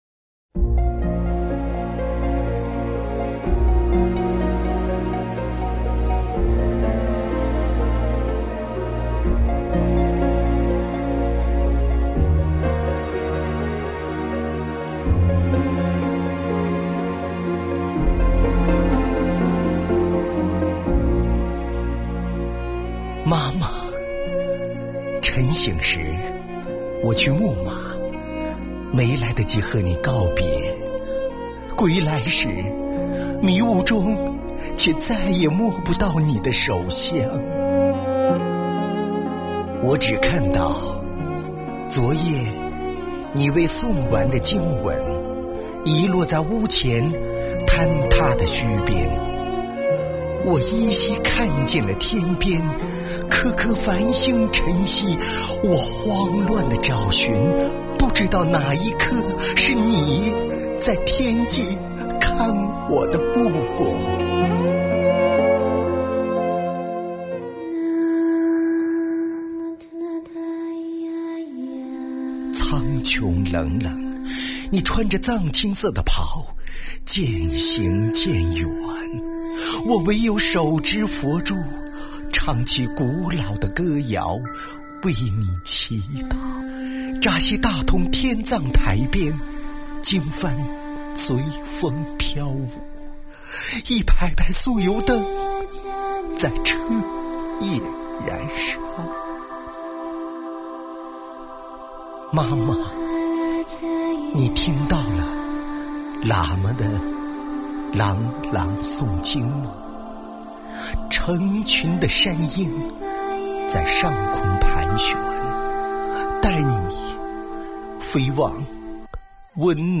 [24/4/2010]朗诵《格桑花开的时候》